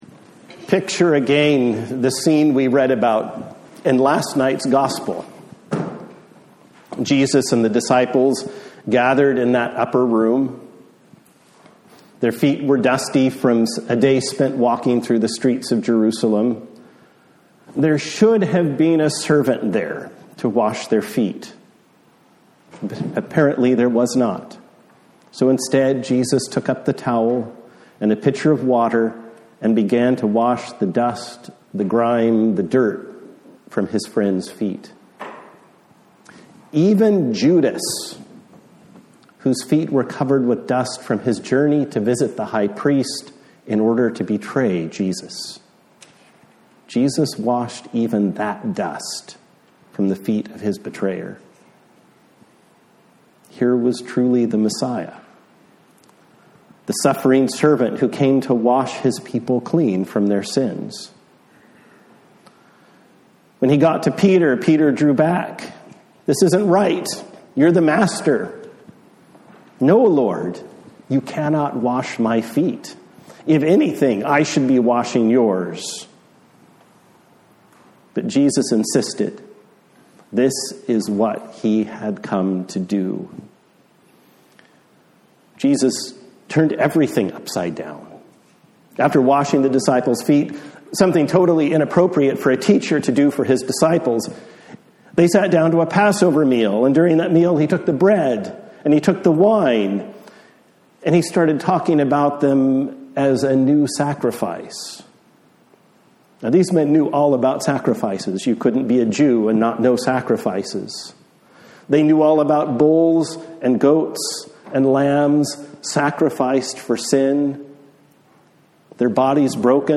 A Sermon for Good Friday